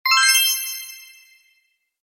礼物UI弹窗1.MP3